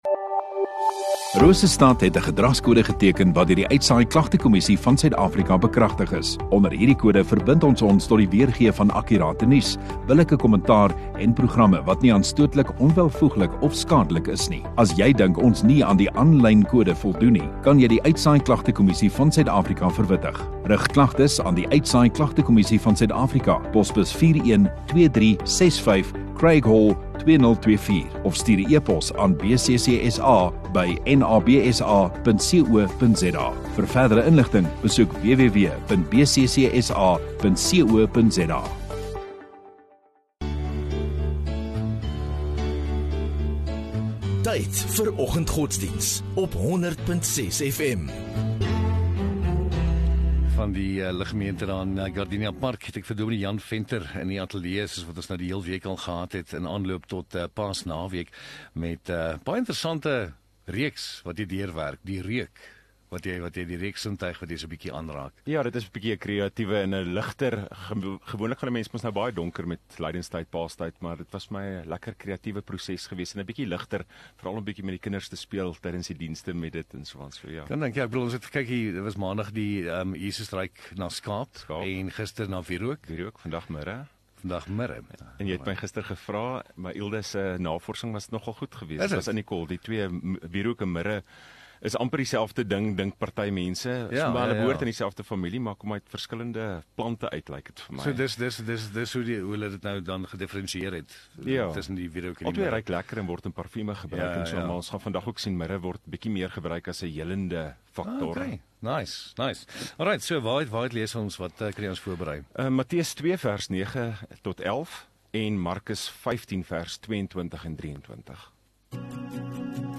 27 Mar Woensdag Oggenddiens